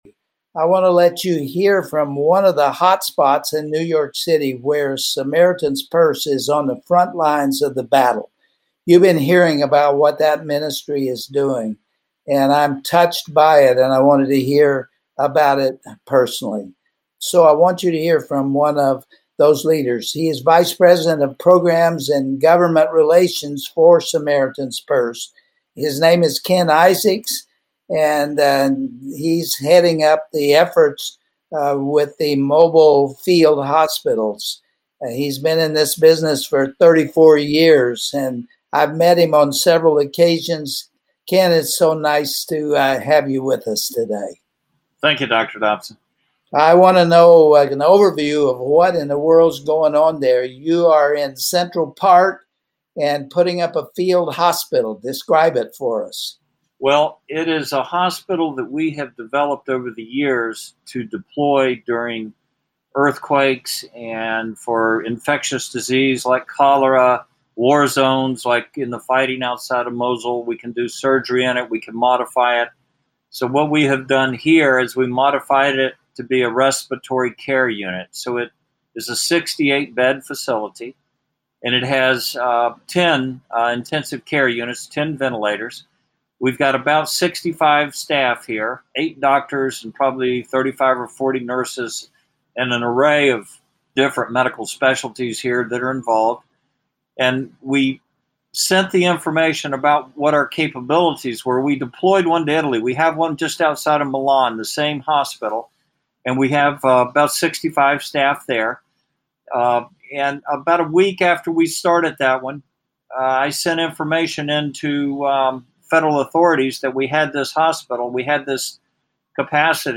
Family Talk radio broadcast